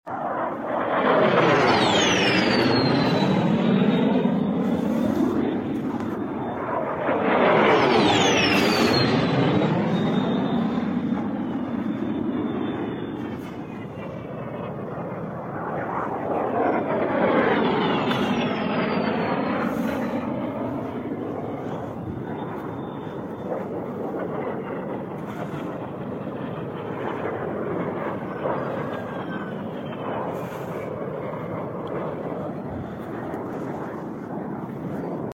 😧 Missiles streak across the sound effects free download